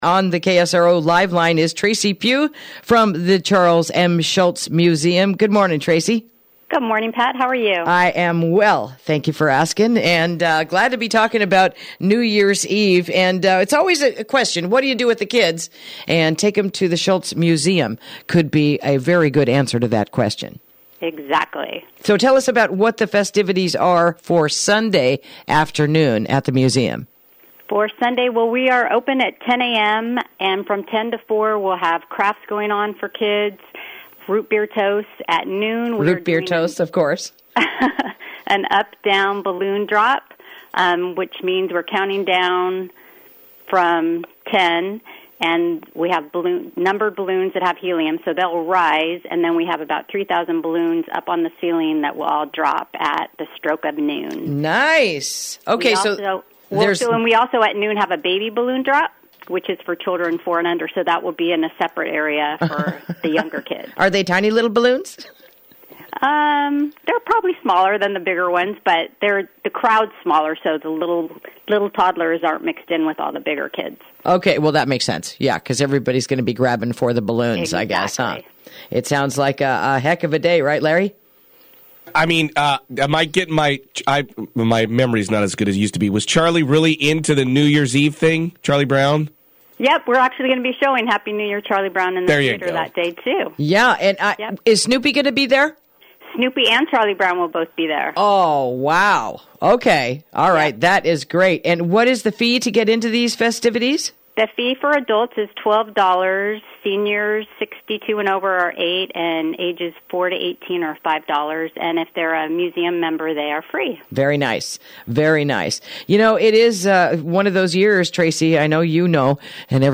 Interview: Happy New Year, Charlie Brown!